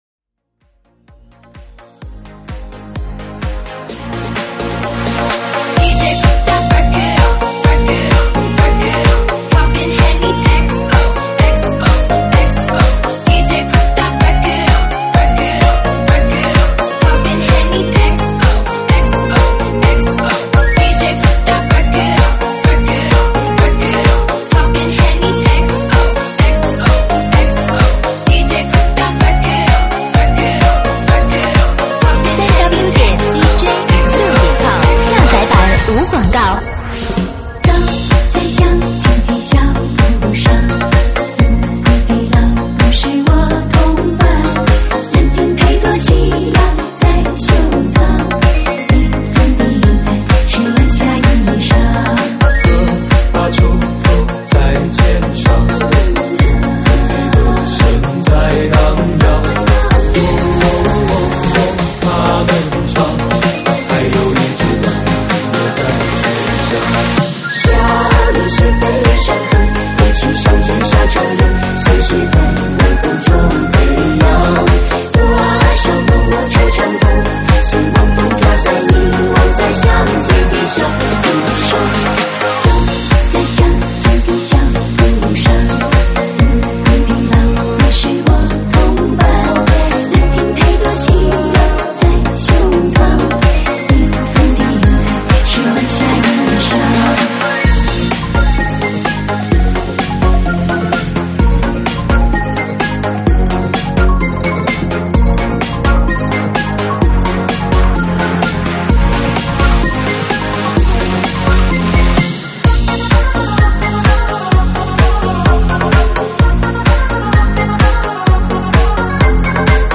收录于(现场串烧)提供在线试听及mp3下载。